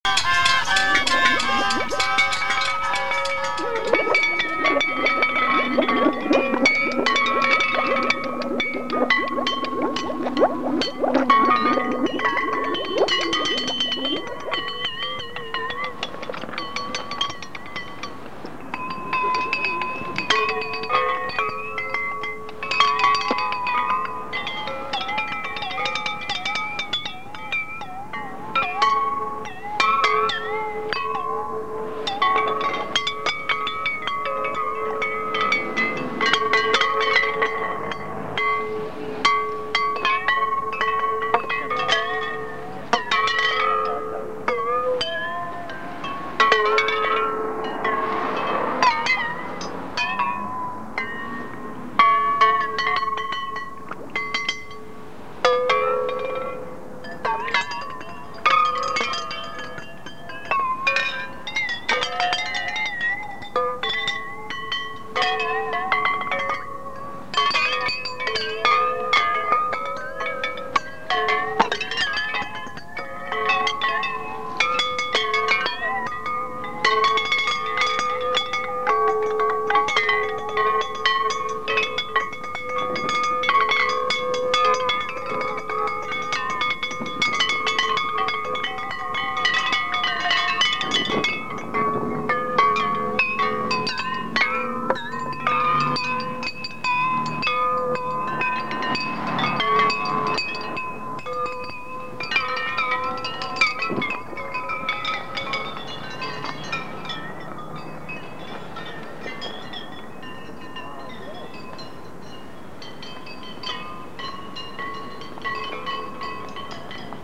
Location: Stevens Square/Red Hot Art